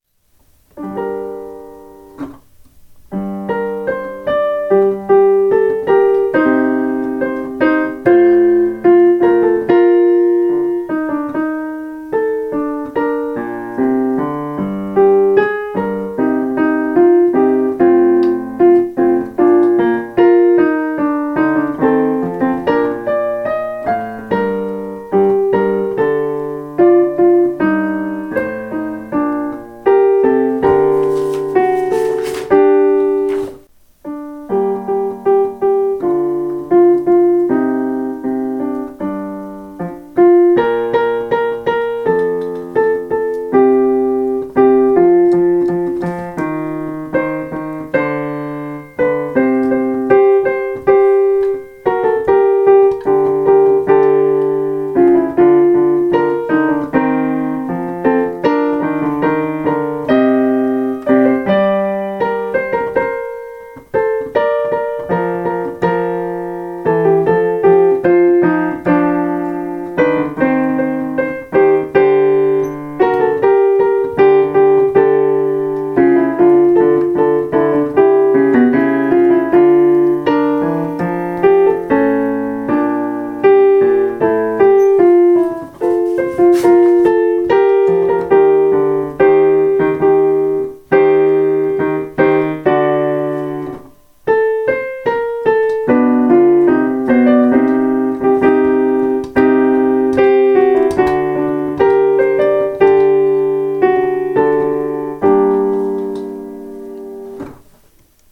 Kammerchor
Aufnahmen zum Üben